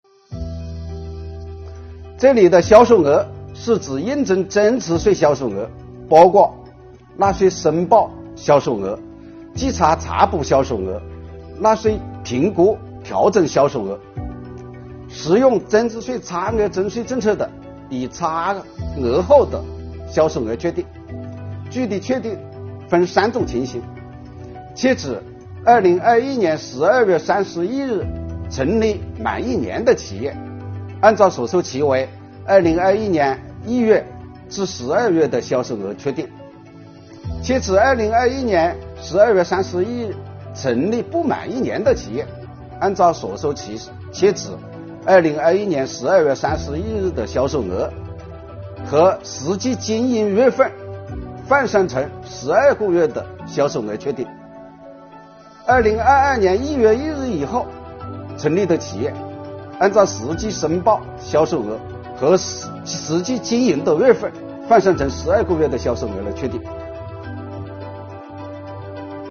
近日，国家税务总局推出新一批“税务讲堂”系列课程，为纳税人缴费人集中解读实施新的组合式税费支持政策。本期课程国家税务总局征管和科技发展司副司长付扬帆担任主讲人，对制造业中小微企业缓缴税费政策解读进行详细讲解，确保大家能够及时、便利地享受政策红利。